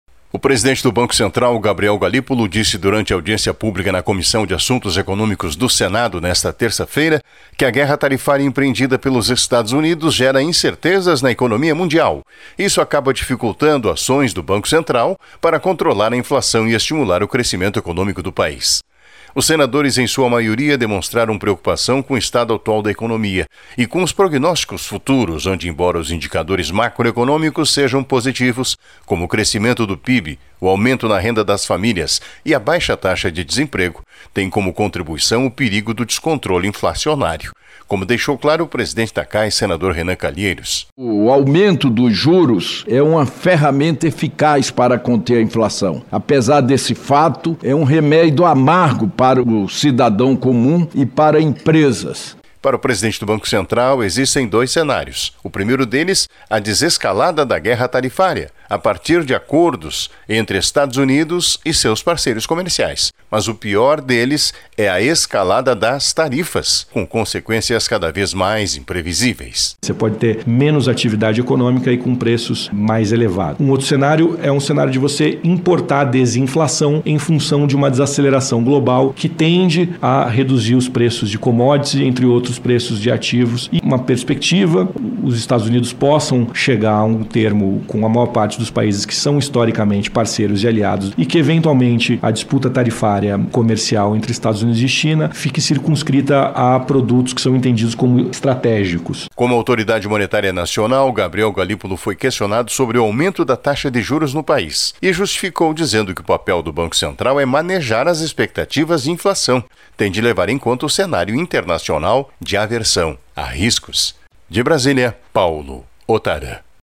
Presidente do Banco Central tenta explicar juros e Taxa SELIC e é questionado por Senadores